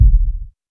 Kicks
KICK.82.NEPT.wav